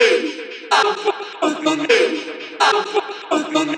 • chopped vocals ping pong house delayed (7).wav
chopped_vocals_ping_pong_house_delayed_(7)_Sry.wav